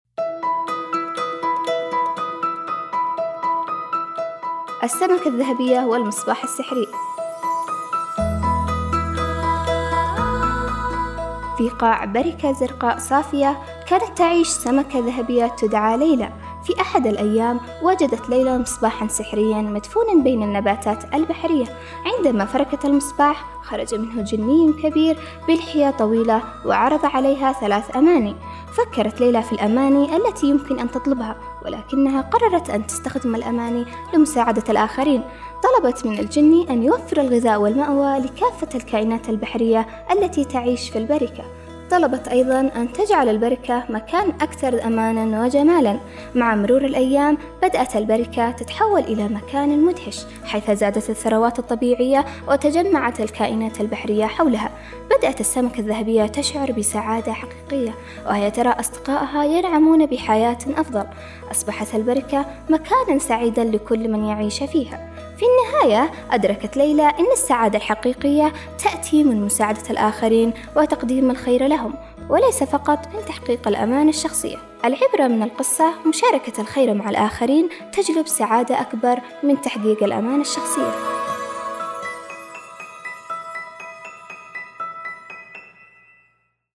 قصة قصيرة